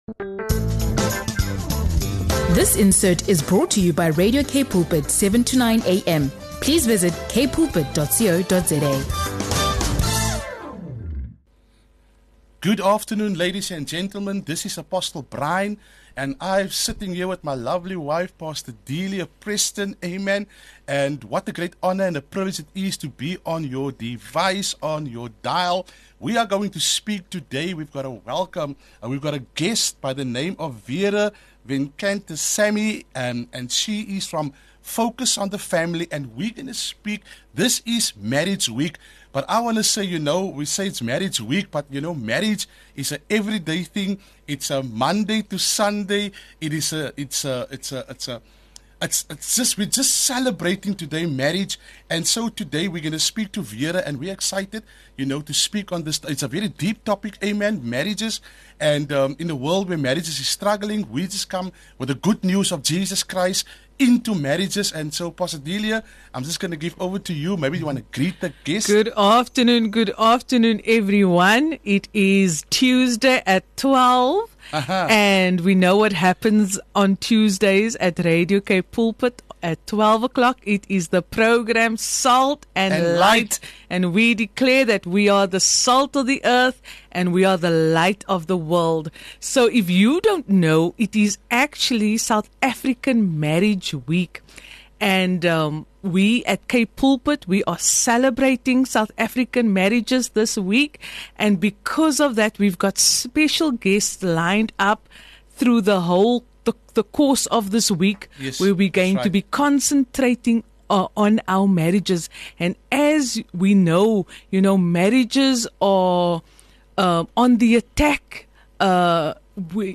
The discussion highlights God’s design for marriage, the importance of covenant over contract, and the power of commitment through life’s highs and lows. With personal testimonies, biblical insights, and practical advice, the program encourages couples to build their marriages on the rock of Christ, cultivate intentional love, and seek support when needed. Prayer is offered for unity, restoration, and God’s blessing over marriages across South Africa.